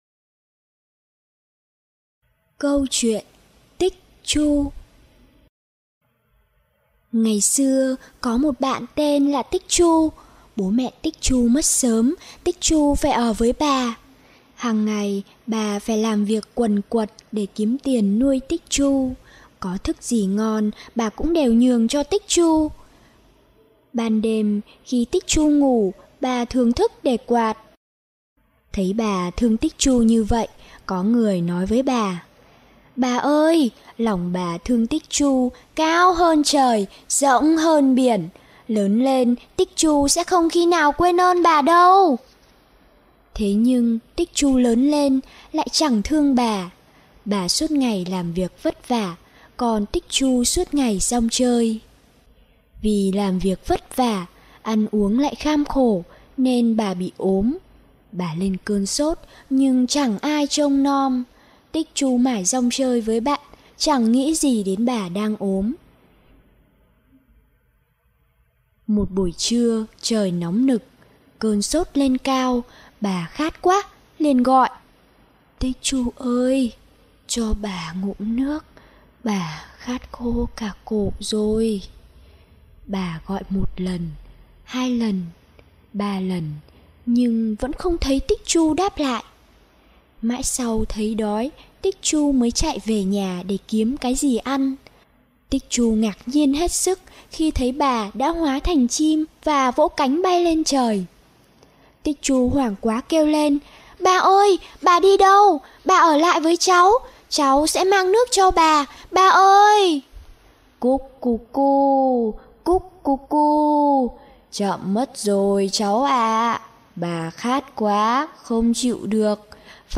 Sách nói | Cậu bé Tích Chu